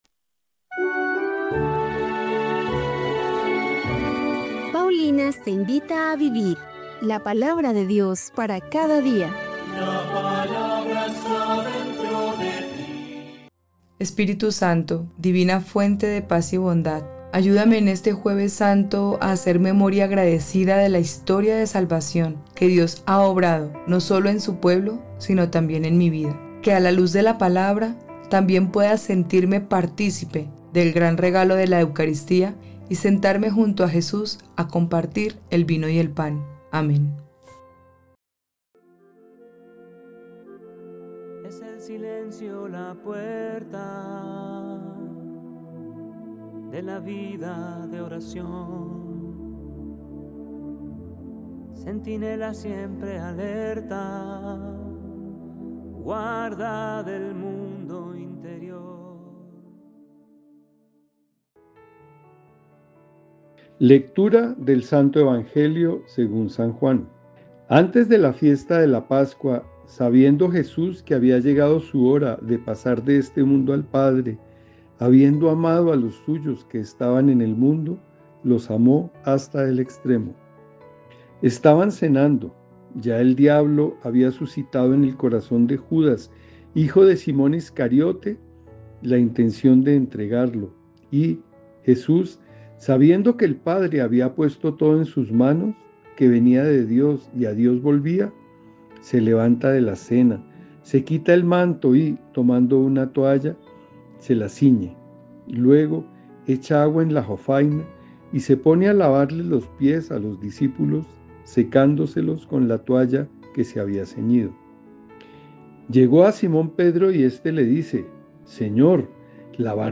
Lectura de los Hechos de los Apóstoles 8, 1b-8